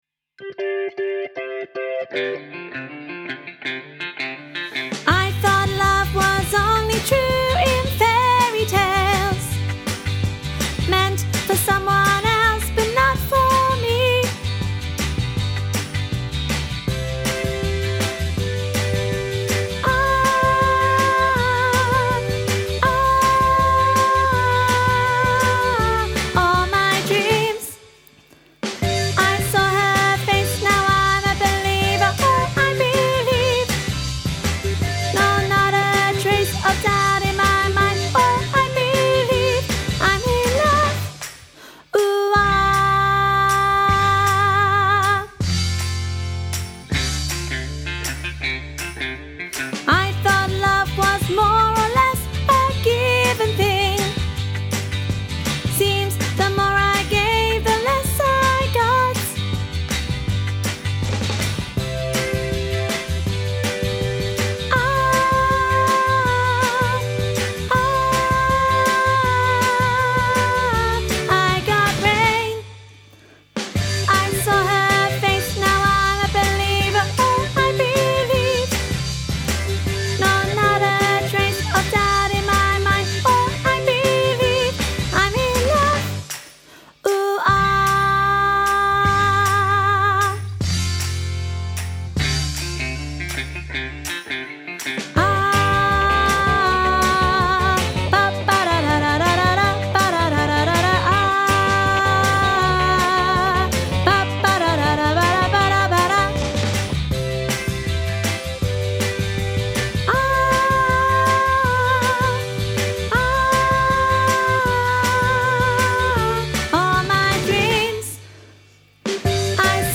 Training Tracks for I'm a Believer
im-a-believer-soprano.mp3